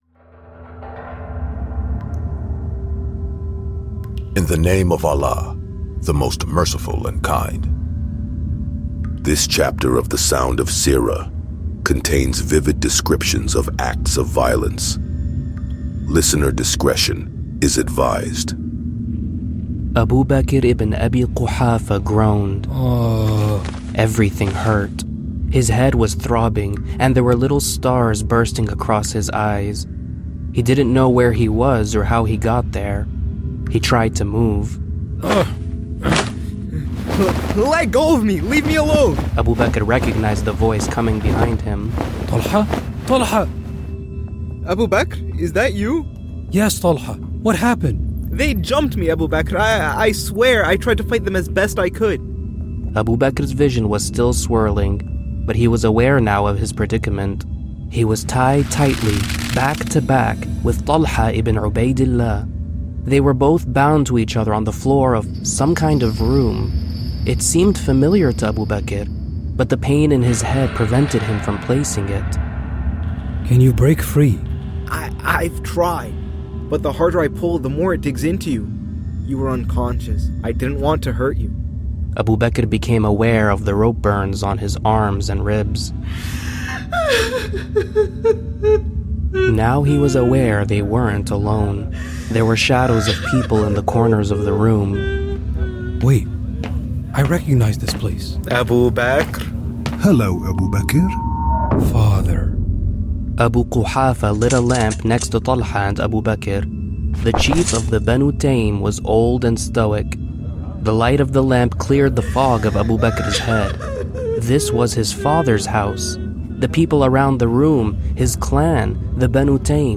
Experience the story of the Prophet Muhammad like never before. This audio adventure is complete with sound effects, actors, and ambiances to make the story of the Islamic prophet come to life.